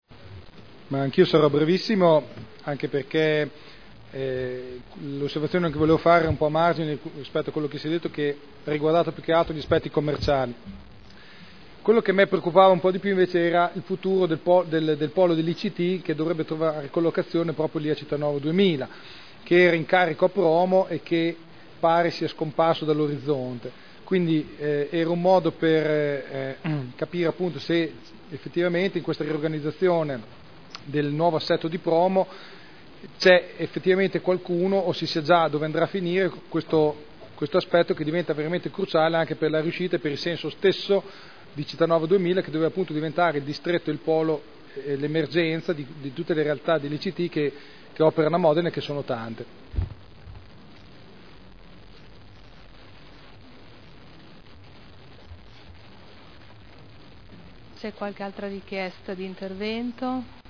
Enrico Artioli — Sito Audio Consiglio Comunale